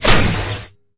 metal1.ogg